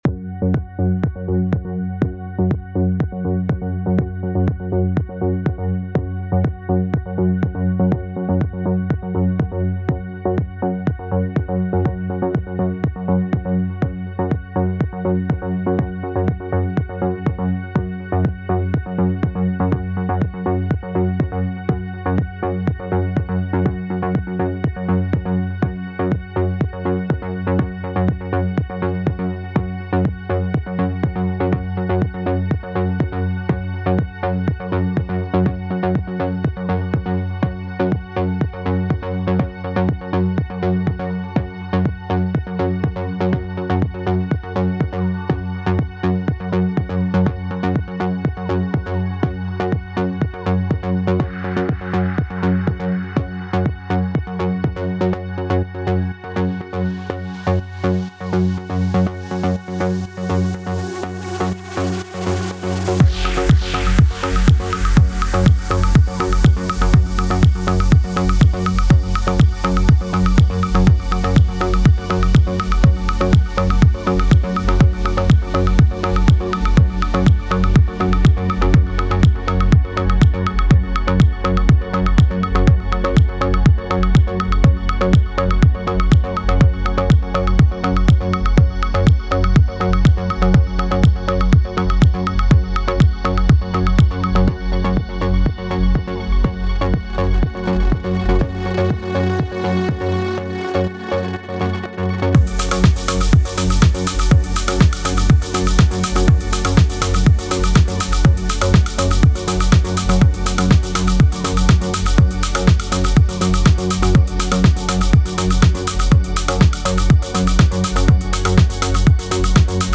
дорогие форумчане,прошу оценить демо версию Melodic techno
работаю над треком,демо версия,без сведения и мастеринга